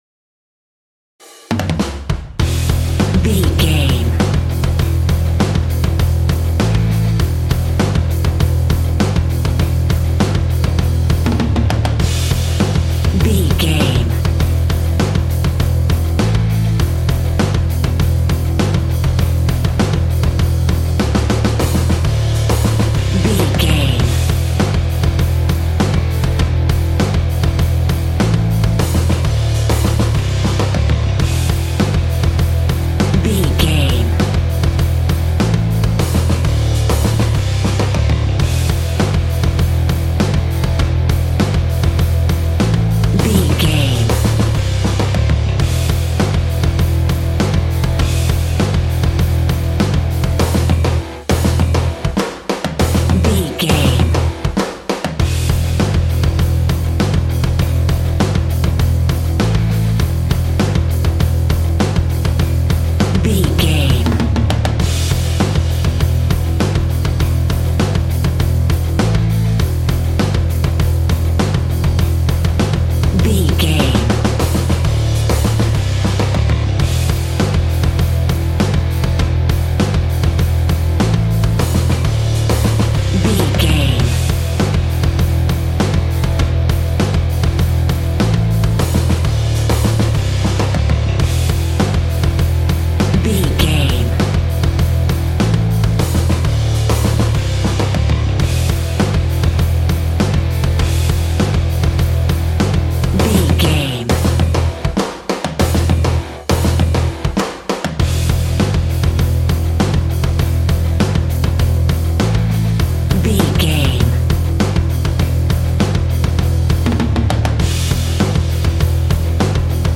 Aeolian/Minor
angry
aggressive
electric guitar
drums
bass guitar